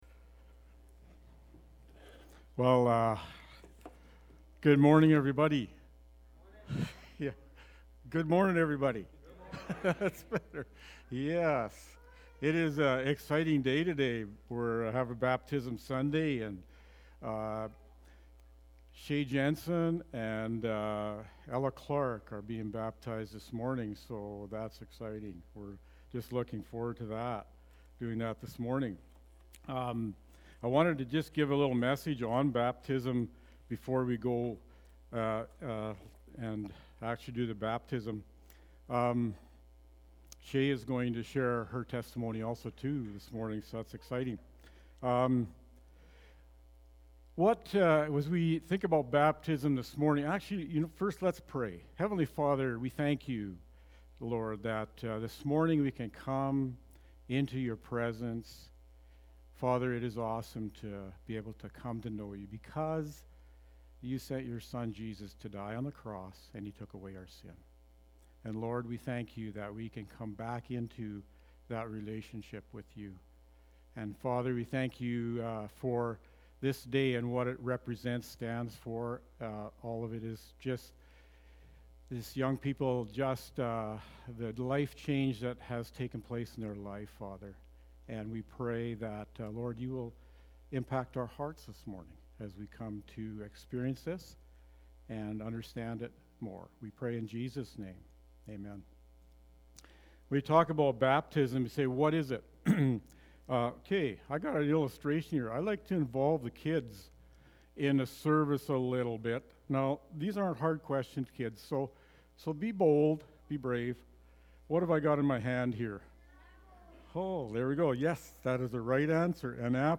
October-25-Sermon.mp3